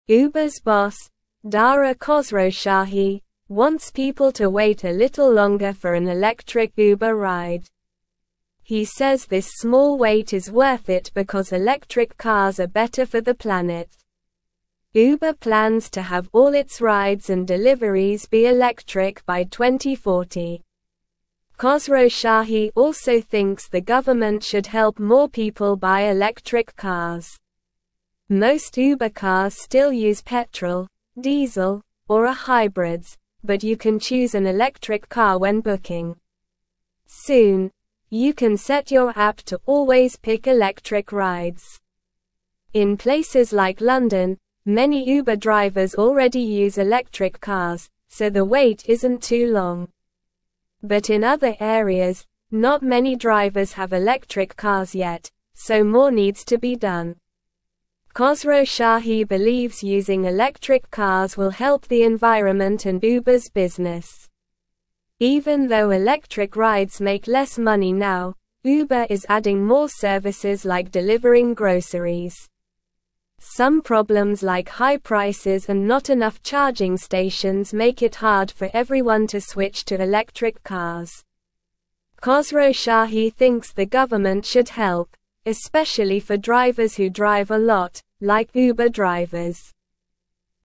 Slow
English-Newsroom-Lower-Intermediate-SLOW-Reading-Uber-Boss-Wants-More-Electric-Cars-for-Rides.mp3